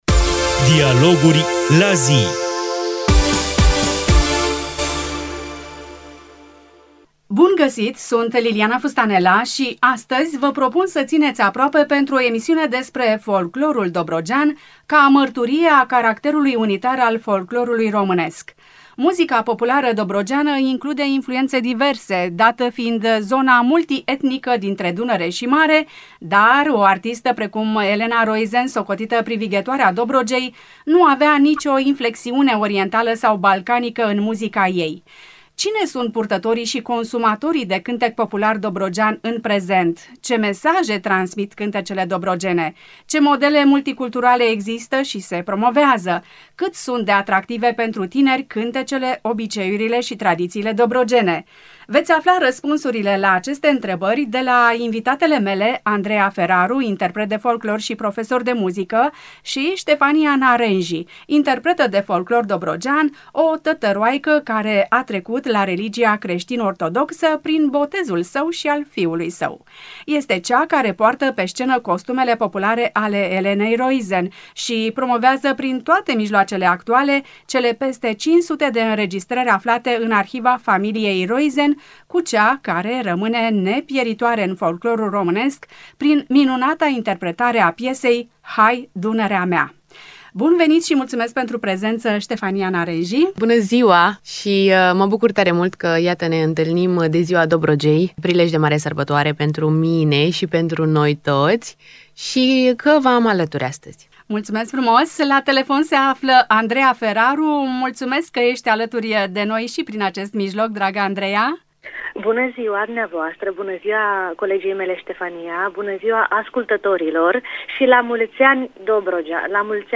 interprete de folclor, invitate în ediția de astăzi